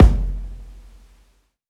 Live_kick_3.wav